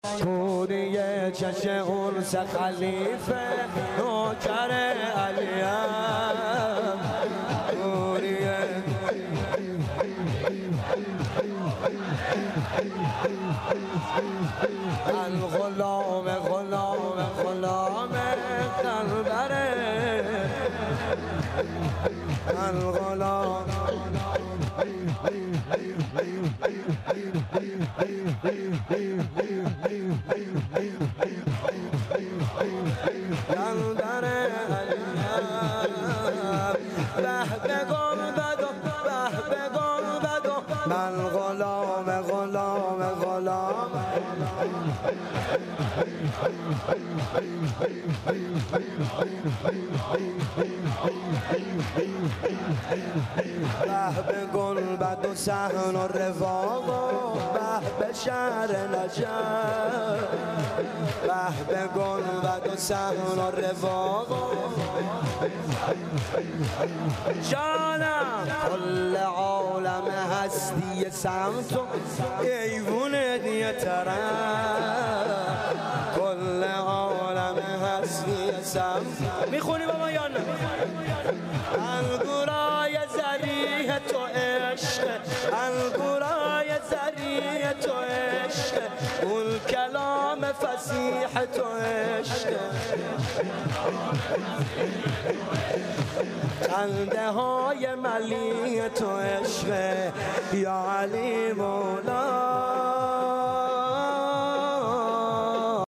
شور
شهادت آقا امام صادق علیه السلام